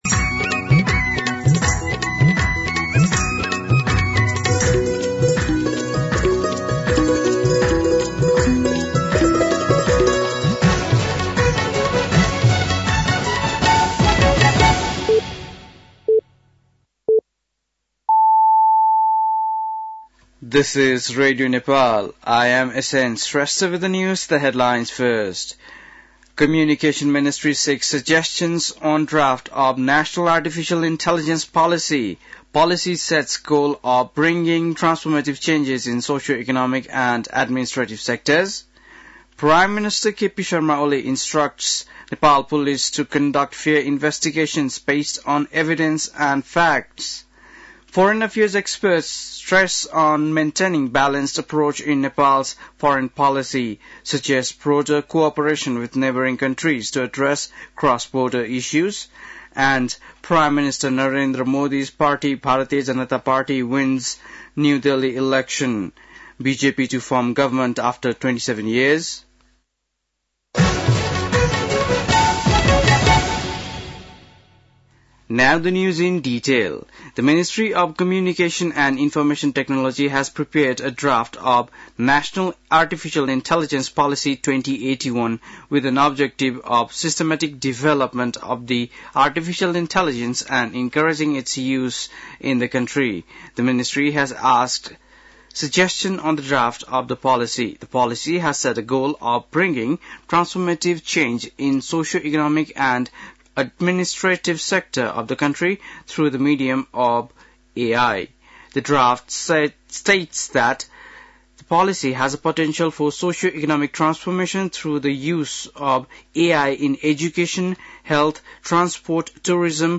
बेलुकी ८ बजेको अङ्ग्रेजी समाचार : २७ माघ , २०८१
8-PM-English-News-10-26.mp3